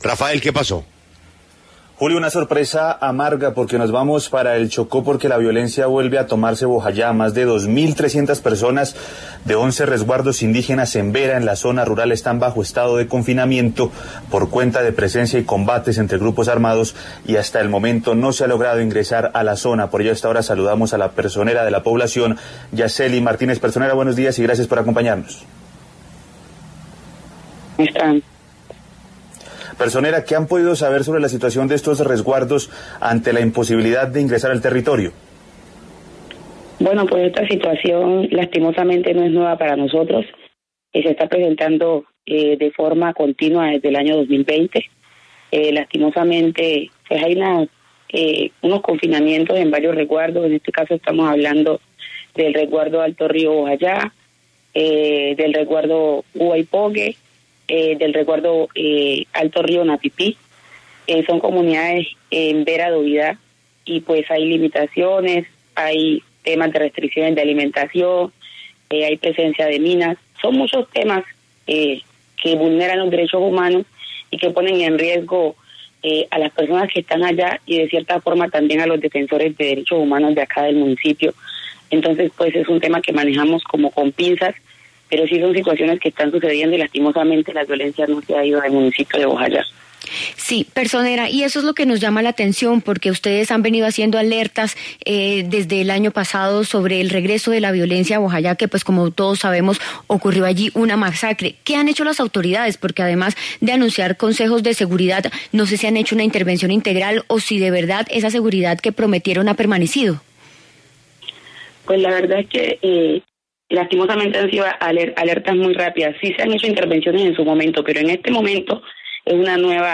Habló en La W Yasselly Martínez, personera de Bojayá, Choco sobre el confinamiento de 2.300 indígenas en este municipio.
En entrevista con La W, la personera de Bojayá (Chocó), Yasselly Martínez, se refirió a la grave crisis humanitaria que afronta esa población ante el confinamiento de 2.326 indígenas Embera pertenecientes a once resguardos, los cuales están afrontando toques de queda, amenazas y ataques a sus lanchas para limitar la movilidad.